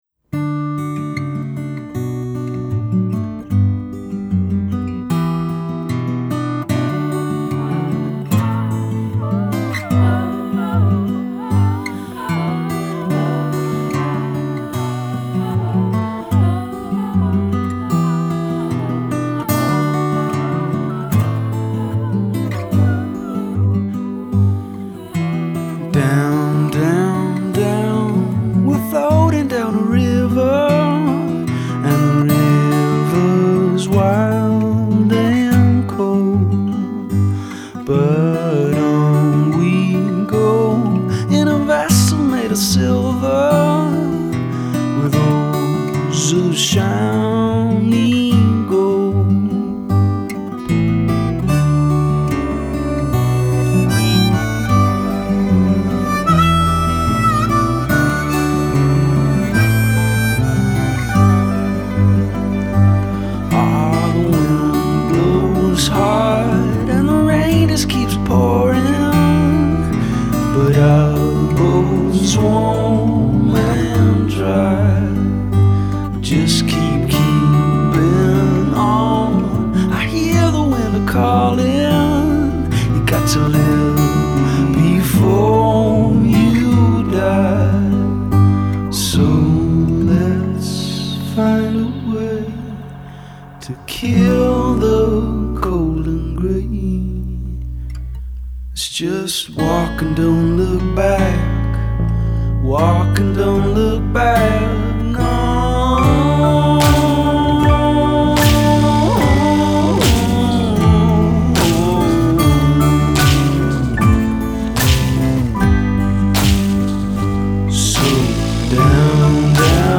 a beautiful melody and some nice background vocal touches